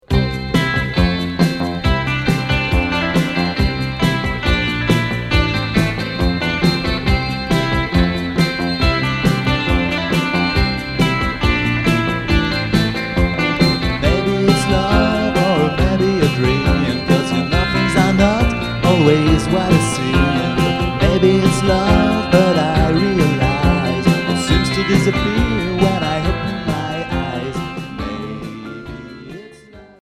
Pop rock 60's Deuxième 45t retour à l'accueil